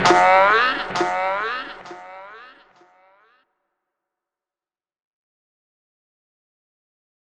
DMV3_Vox 1.wav